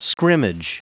Prononciation du mot scrimmage en anglais (fichier audio)
Prononciation du mot : scrimmage
scrimmage.wav